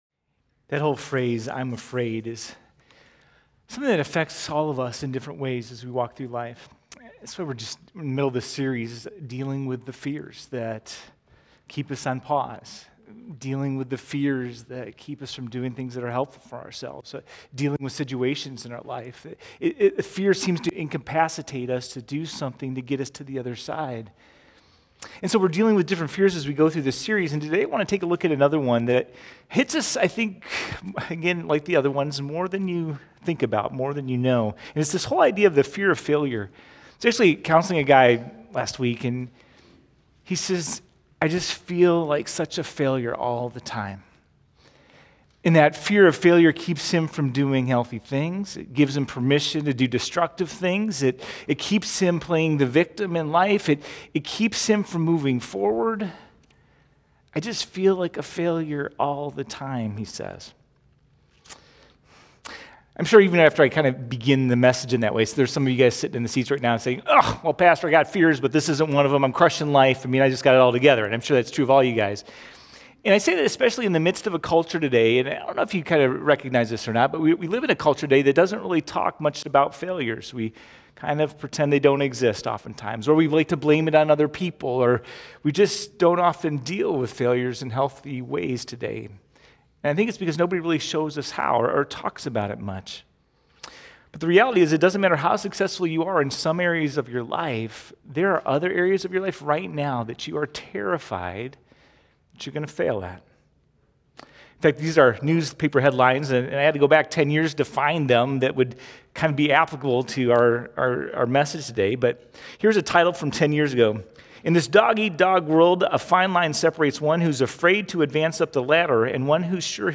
515Sermon.mp3